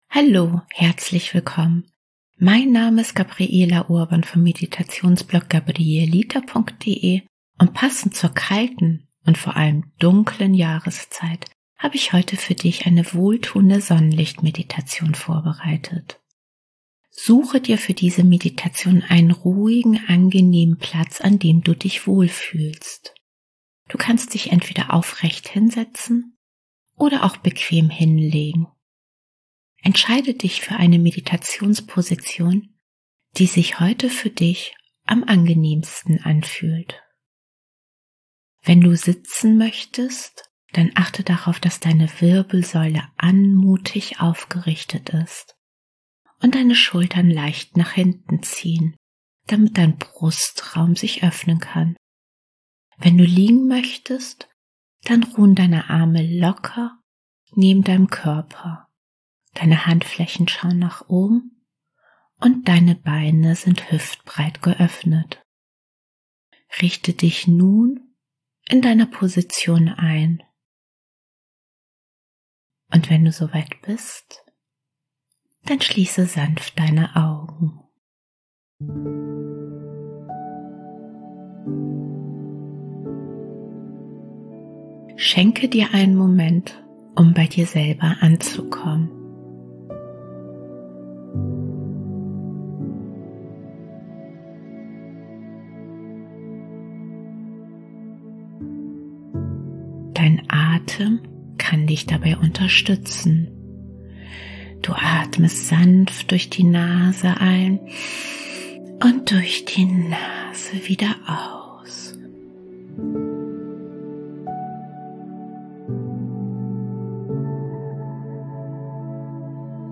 und in dieser Sonnenlicht Meditation begleite ich dich auf eine entspannende Fantasiereise an einen wunderschönen See an einem Sommertag. Durch die geführte Visualisierung von goldenem Sonnenlicht, das deinen ganzen Körper durchströmt, lösen sich innere Kälte und Anspannungen. Die sanfte Körperreise aktiviert deine innere Wärmequelle und schenkt dir ein Gefühl tiefer Geborgenheit.